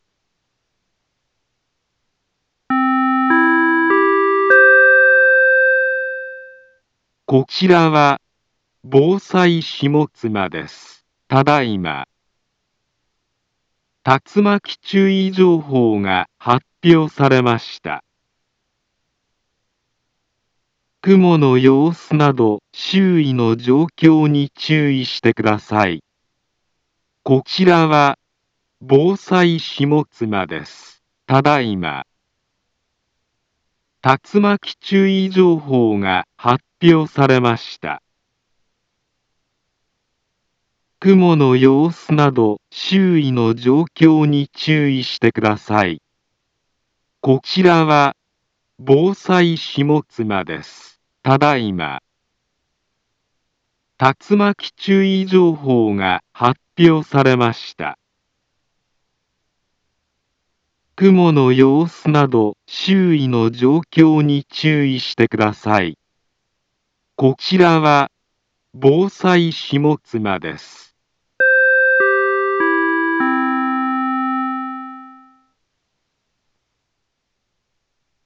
Back Home Ｊアラート情報 音声放送 再生 災害情報 カテゴリ：J-ALERT 登録日時：2024-07-27 19:29:20 インフォメーション：茨城県北部、南部は、竜巻などの激しい突風が発生しやすい気象状況になっています。